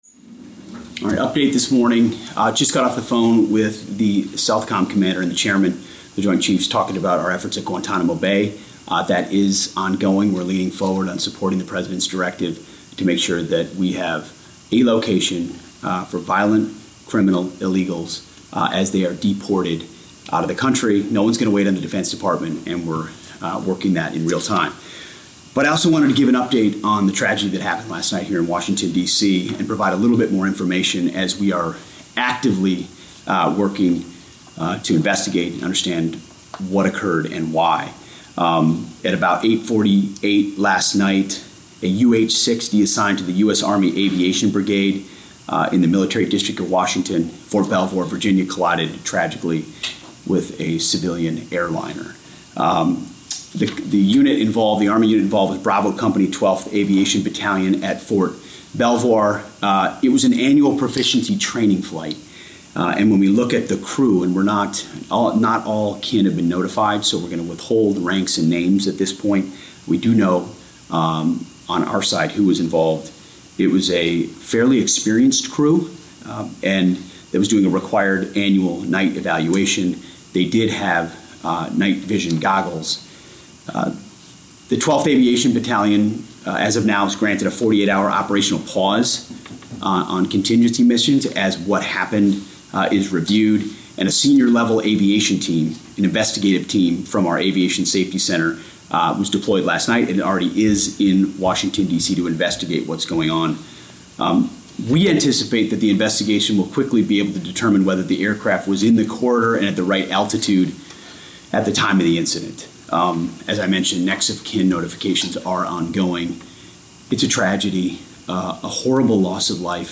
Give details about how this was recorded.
delivered 30 January 2025, Washington, D.C. Audio Note: AR-XE = American Rhetoric Extreme Enhancement